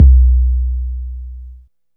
BWB RADIO READY 808 (5).WAV